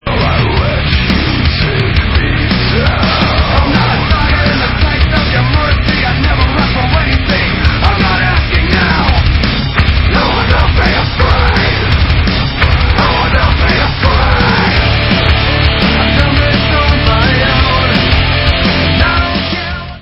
sledovat novinky v kategorii Rock
sledovat novinky v oddělení Heavy Metal